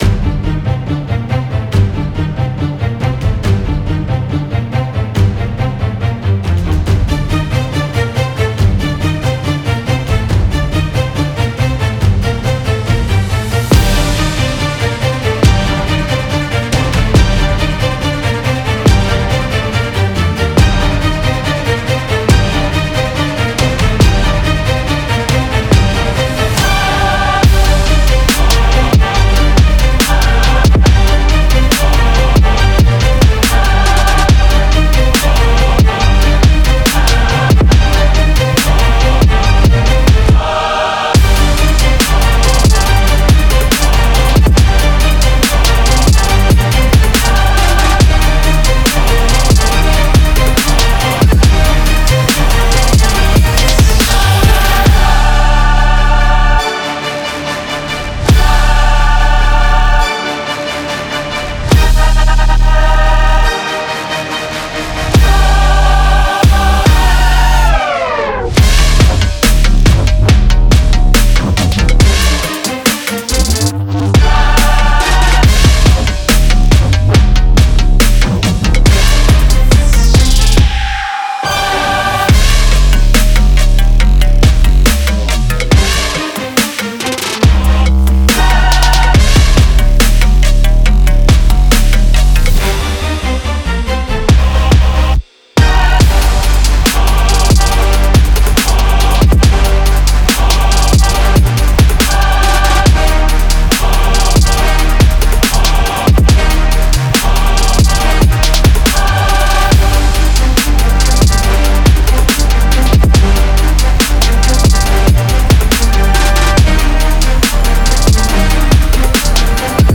Genre: News